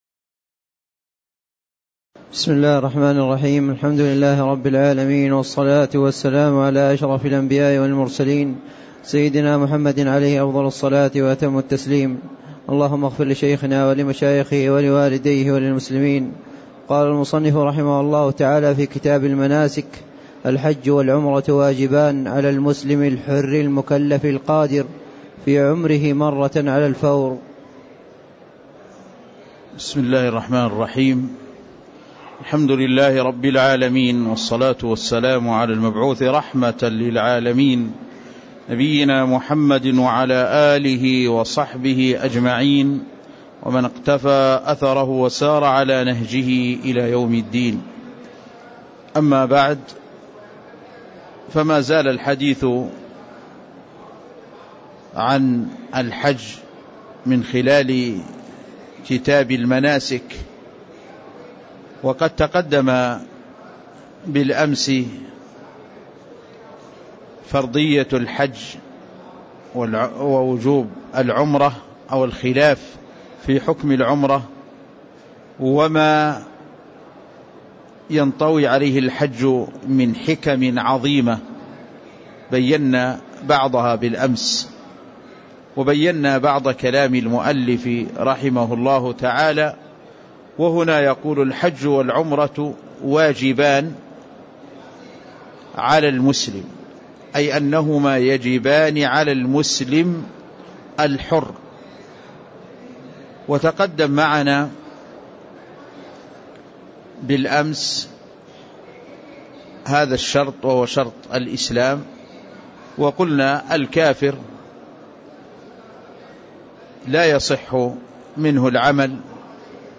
تاريخ النشر ١٥ ذو القعدة ١٤٣٥ هـ المكان: المسجد النبوي الشيخ